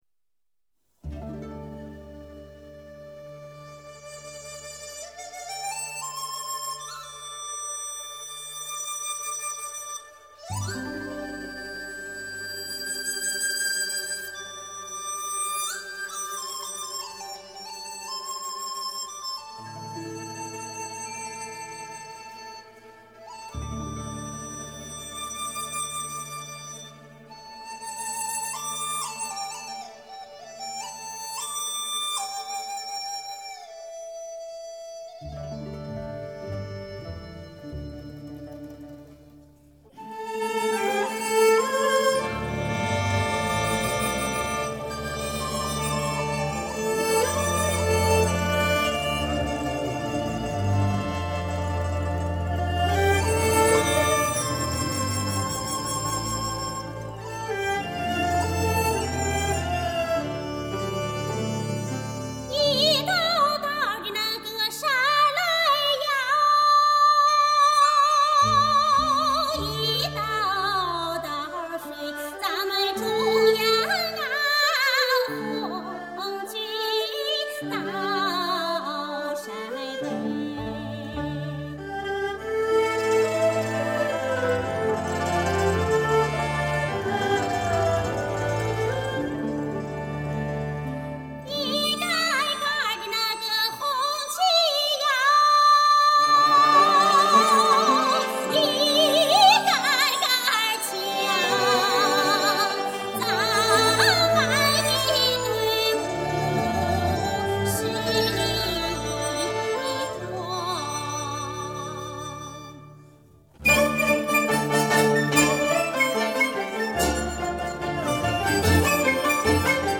地方民歌、戏曲及其他歌类
这就是陕北民歌。